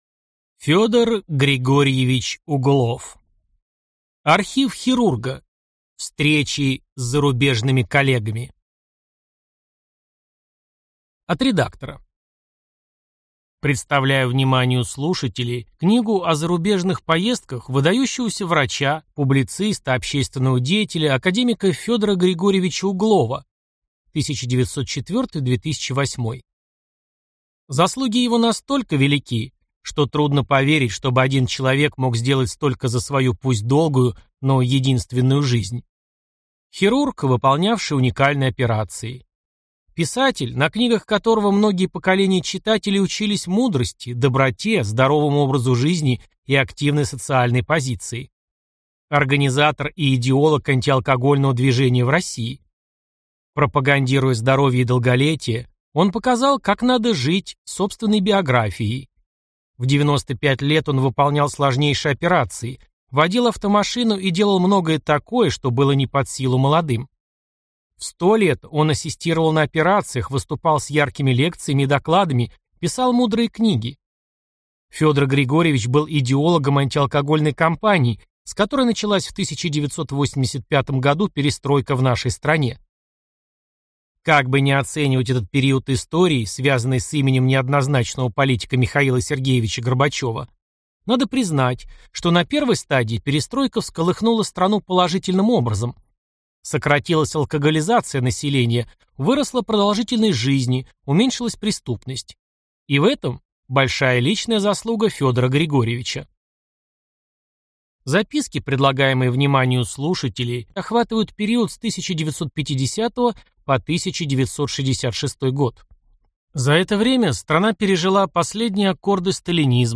Аудиокнига Архив хирурга. Встречи с иностранными коллегами | Библиотека аудиокниг